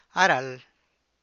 a ral[a ra’ahl]